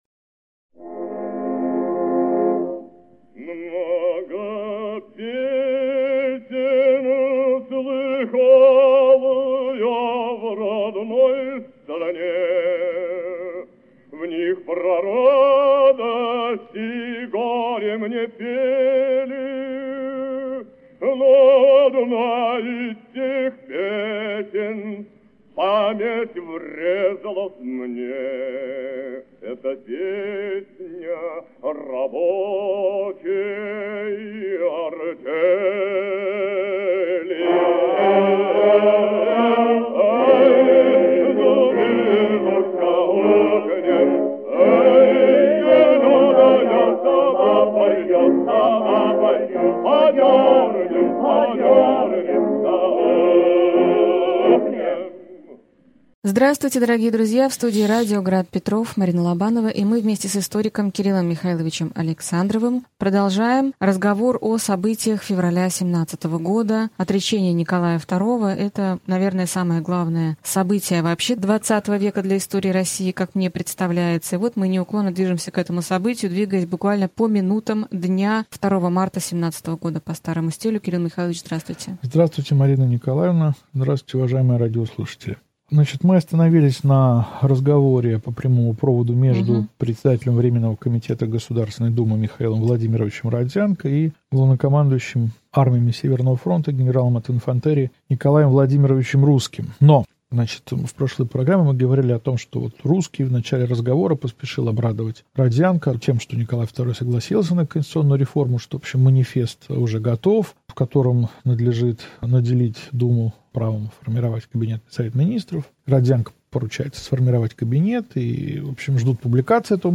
Аудиокнига Февральская революция и отречение Николая II. Лекция 23 | Библиотека аудиокниг